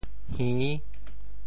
潮州 hin6 白 对应普通话: ěr ①听觉和平衡感觉的器官（通称“耳朵”）：～背（bèi） | ～垂 | ～鬓厮磨（mó） | ～穴 | ～聪目明 | ～濡目染。